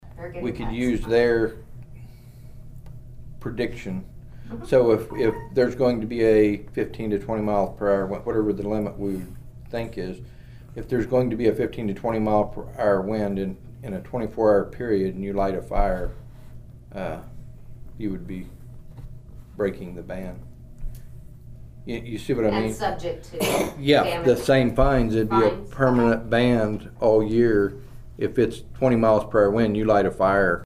The Nowata County Commissioners met on Monday morning at the Nowata County Courthouse Annex.
Commissioner Troy Friddle talked about the enforcement of a burn ban.